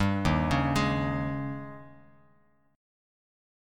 Eb11 chord